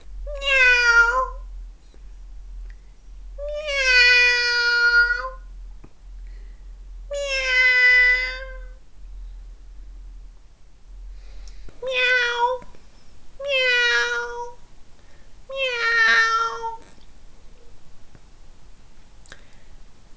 cat1.wav